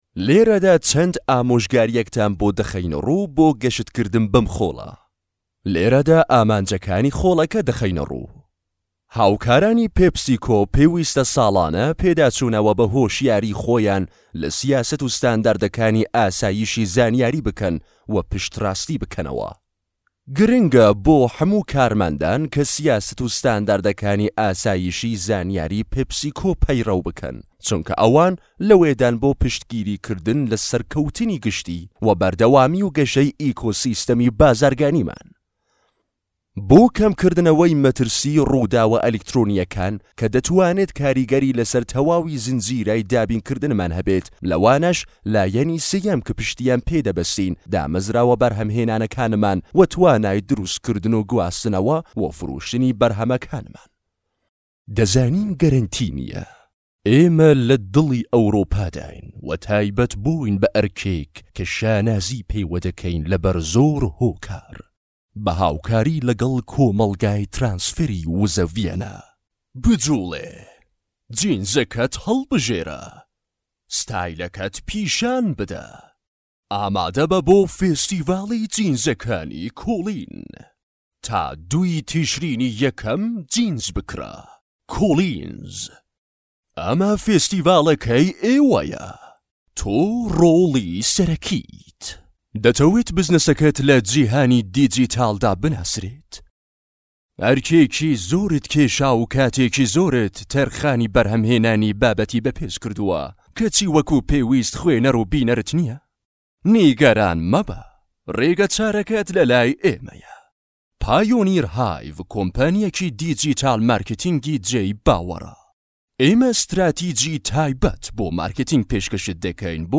Male
Adult
Educational